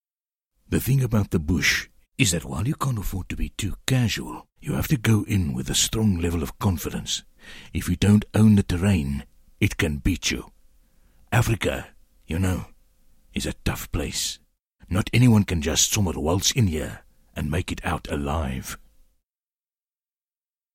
Male
Adult (30-50)
Rich modulated, medium age range, warm authorititave to lively sales.
Foreign Language
66247tw_SA_afrikaans_accent.mp3